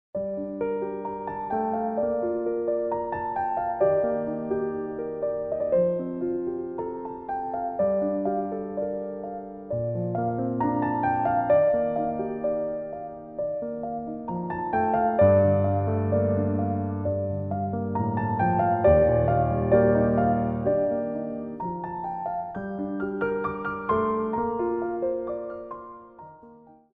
Piano Arrangements of Pop & Rock for Ballet Class
4/4 (8x8)